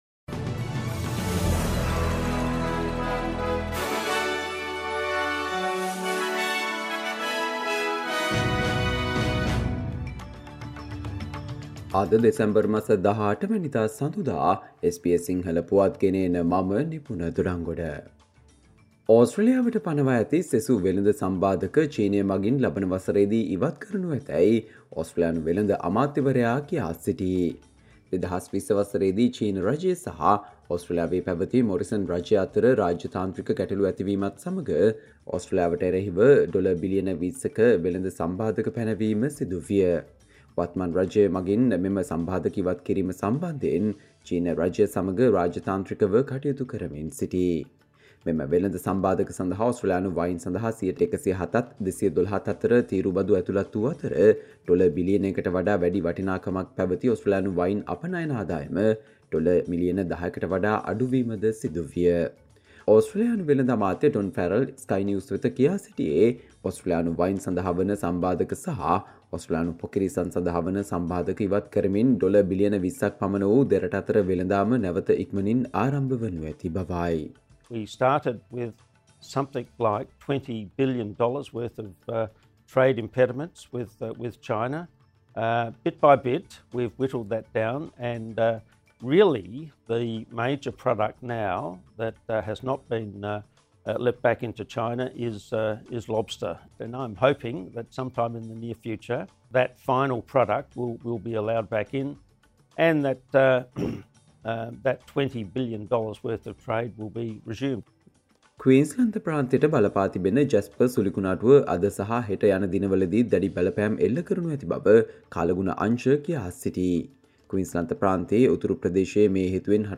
Australia news in Sinhala, foreign and sports news in brief - listen, Monday 18 December 2023 SBS Sinhala Radio News Flash